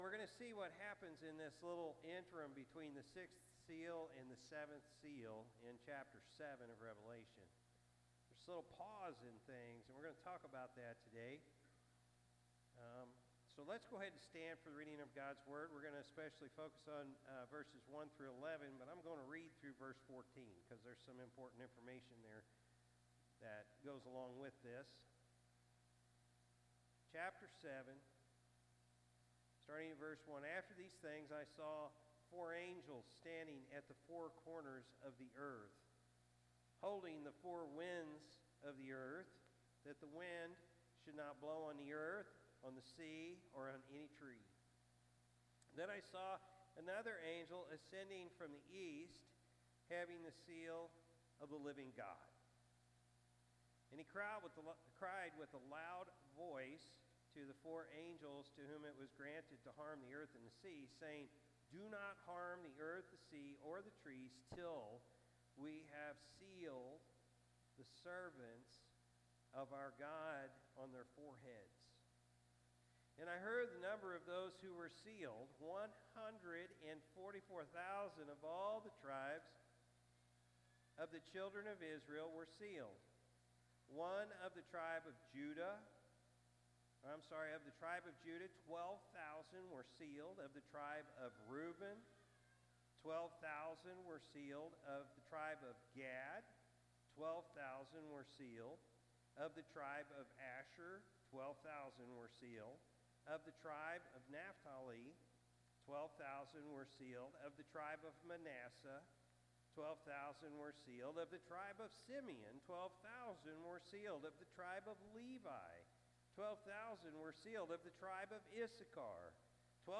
September-1-2024-Morning-Service.mp3